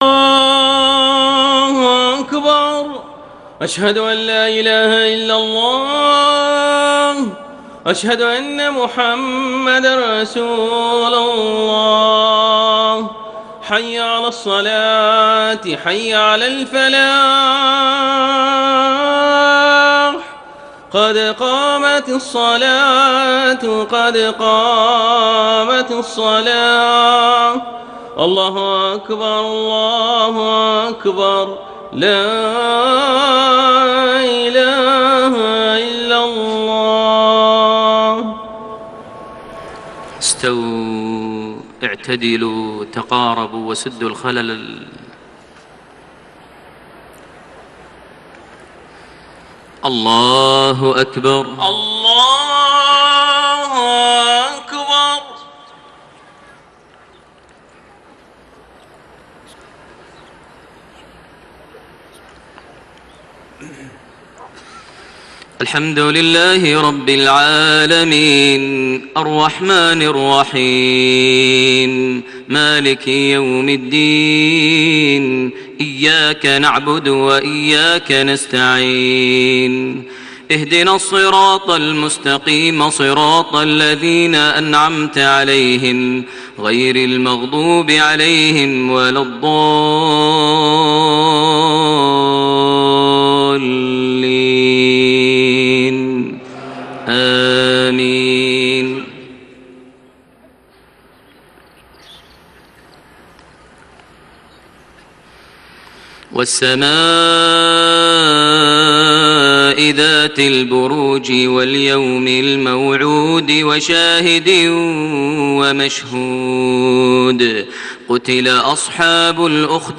صلاة المغرب 2 شوال 1432هـ سورة البروج > 1432 هـ > الفروض - تلاوات ماهر المعيقلي